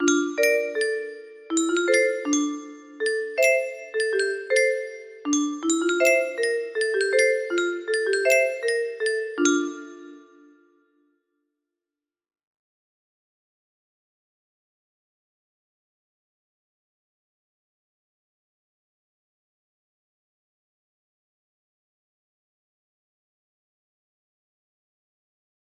HBH music box melody
Grand Illusions 30 (F scale)